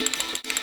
Ratcheting.wav